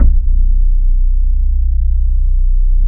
BASS 7    -L.wav